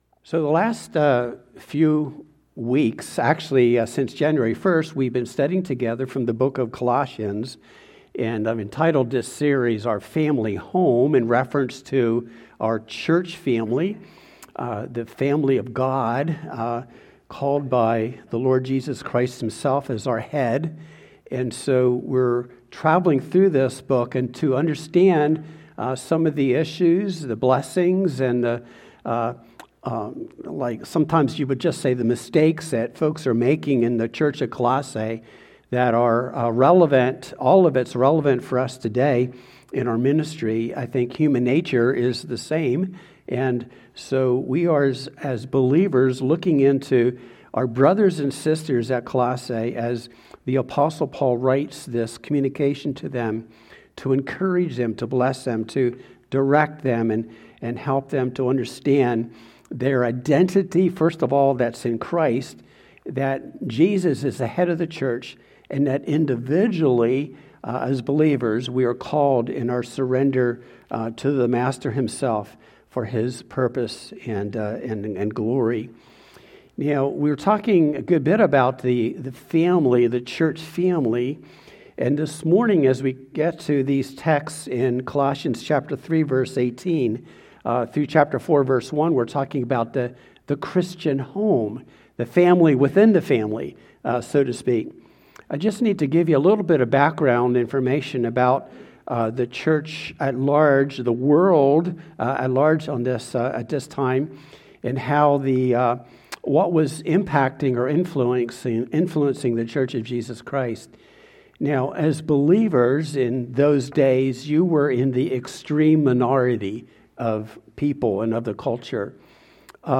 2-22-26-Sermon-Family-Relationships.mp3